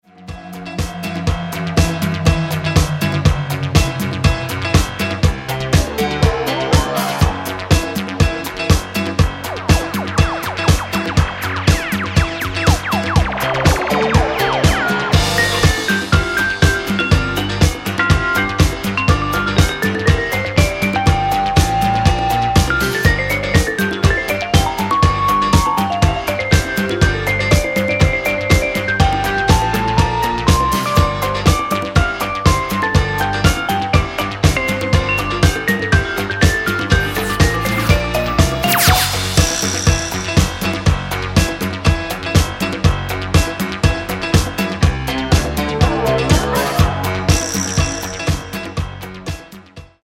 Genere:   Disco | Pop | New Wave